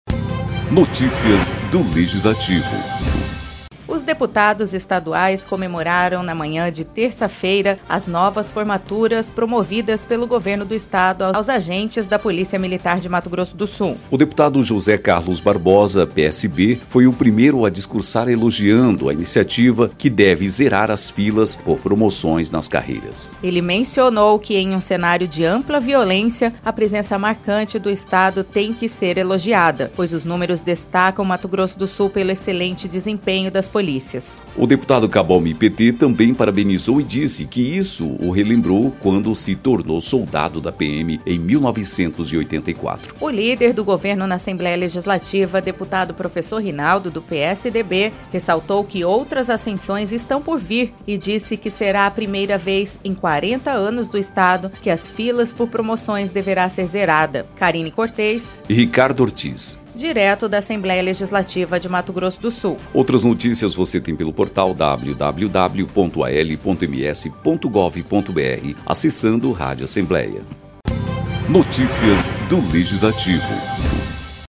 O deputado José Carlos Barbosa (PSB) foi o primeiro a discursar elogiando a iniciativa que deve zerar as filas por promoções nas carreiras.